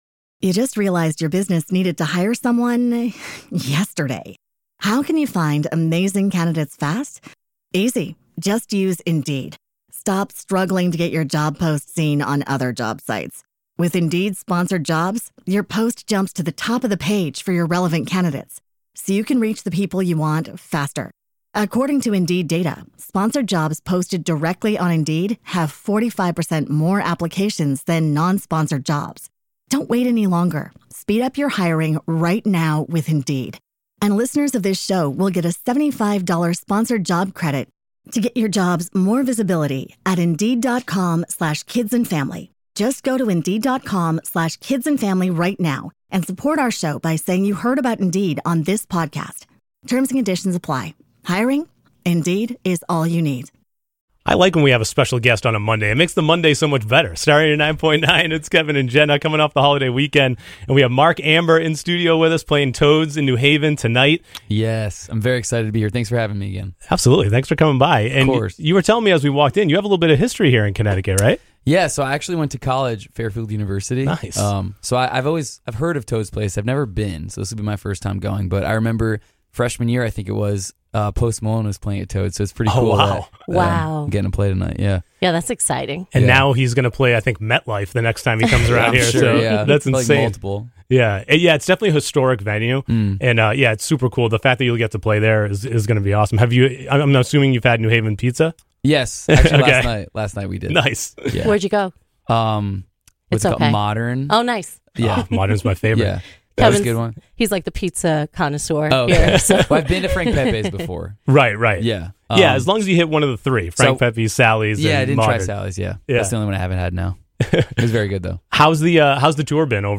Today, Mark Ambor joins us in the studio to talk about his song "Belong Together" and his current tour. He shares what life on the road is really like and how each city offers a unique experience.